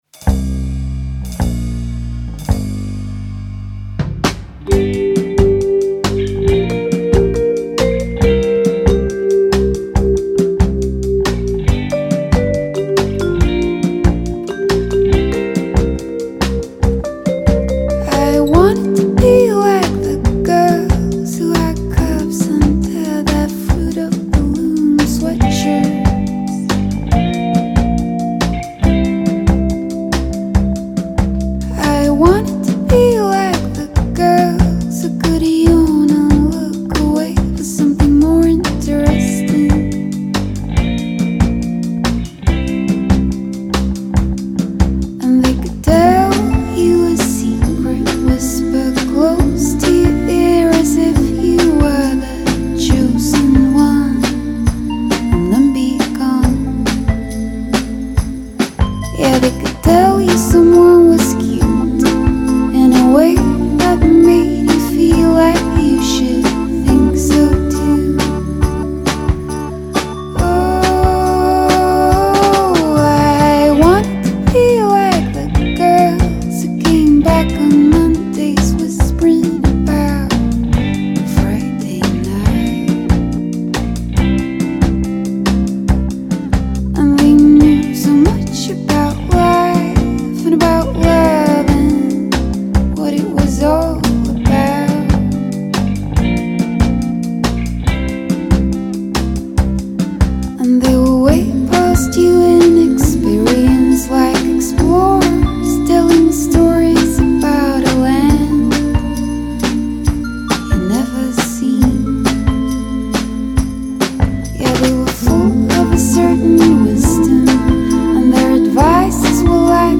languida bossanova